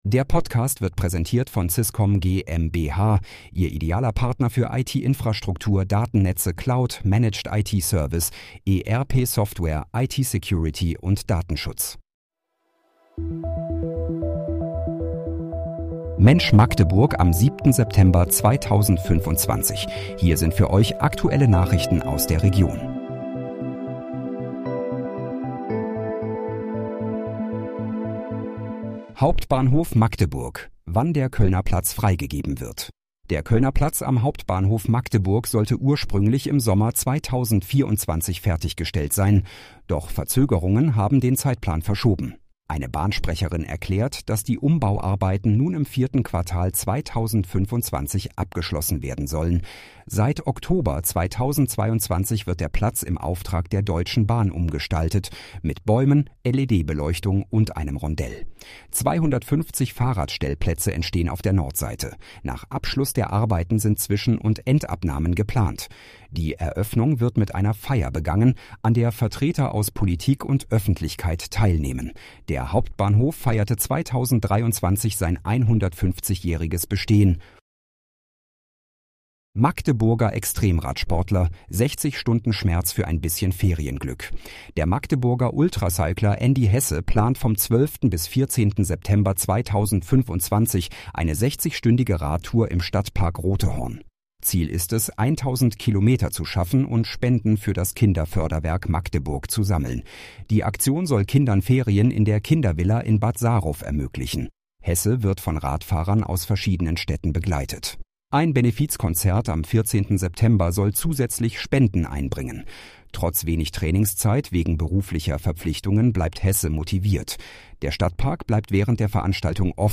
Mensch, Magdeburg: Aktuelle Nachrichten vom 07.09.2025, erstellt mit KI-Unterstützung
Nachrichten